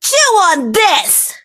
bibi_ulti_vo_01.ogg